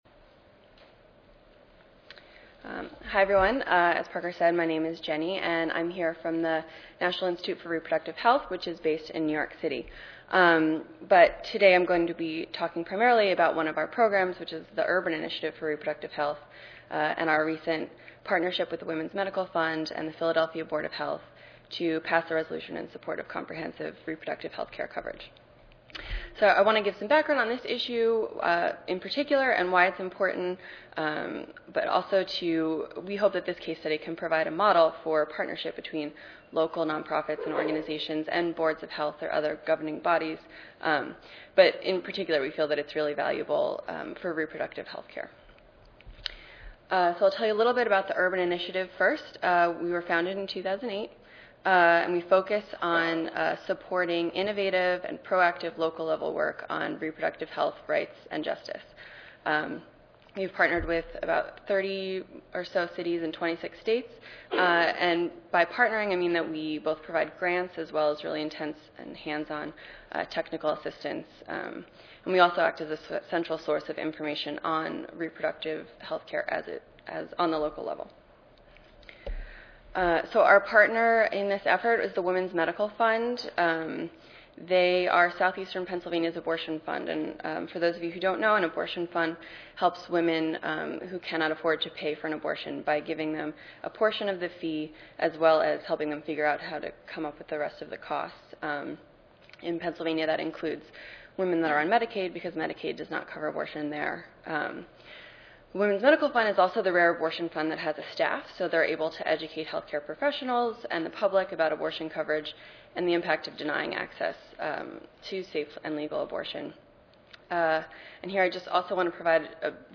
141st APHA Annual Meeting and Exposition (November 2 - November 6, 2013): Approach to Developing a Collaborative, Comprehensive Public Health Strategy